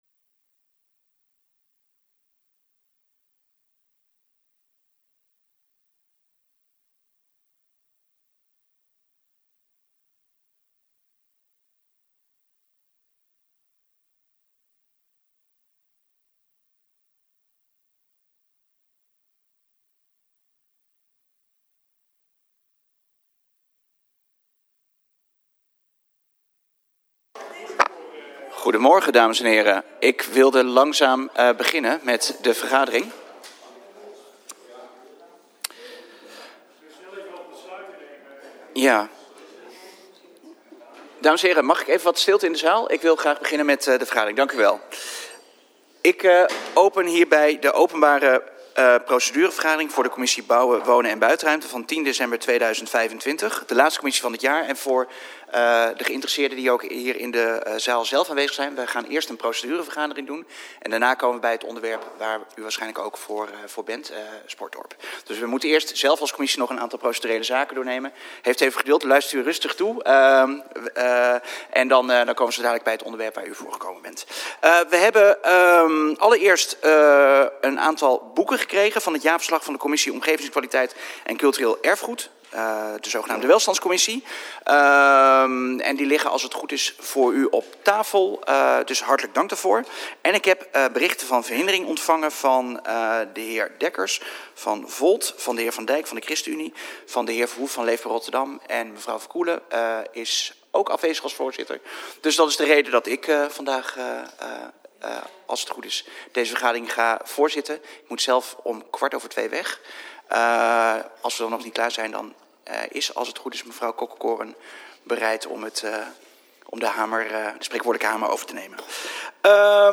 Agenda RotterdamRaad - Commissie Bouwen, Wonen en Buitenruimte (2022-2026) Reguliere commissievergadering woensdag 10 december 2025 09:30 - 17:00 - iBabs Publieksportaal
Door een verstoring heeft het eerste deel van de vergadering alleen beeld.